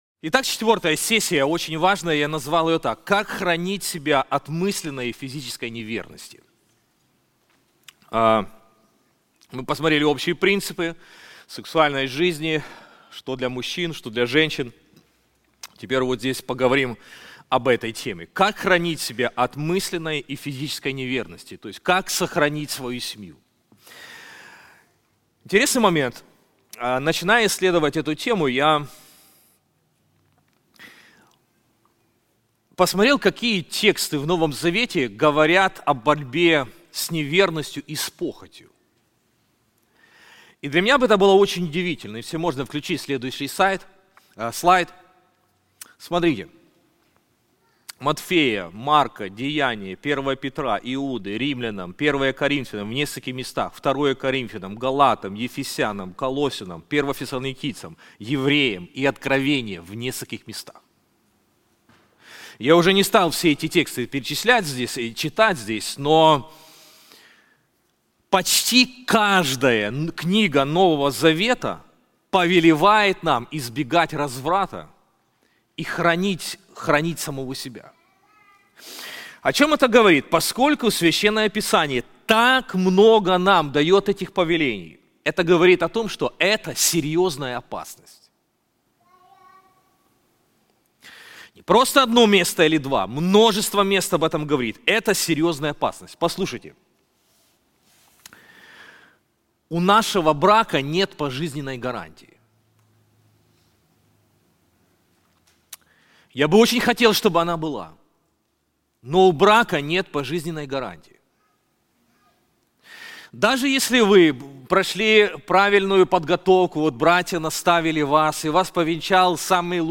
Конференции Господство Христа в сексуальных отношениях